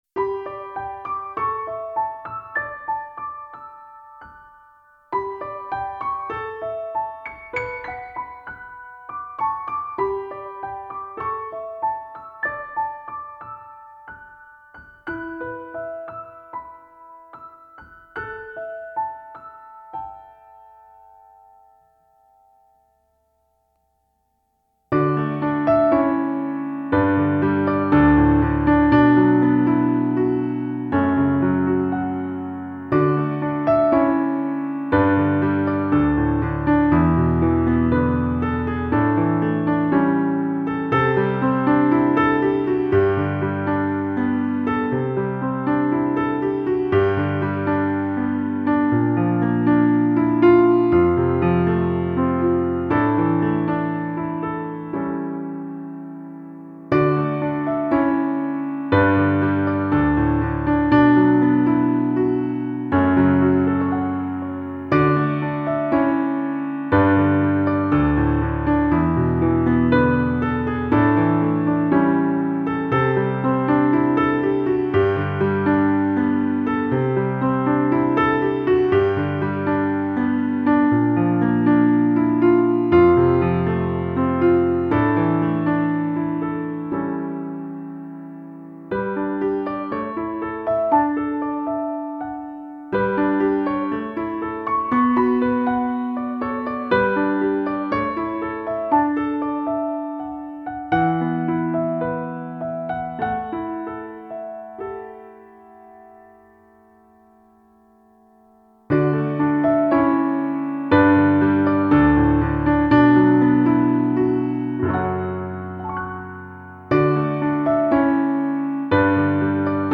钢琴的美，在于纯色素净的音色
高音的清脆、中音的饱和、低音的扎实，还有钢弦与钢弦间的泛音震荡，
更让人感觉温暖、恬适、明亮，藉由琴键流畅的表现，彷彿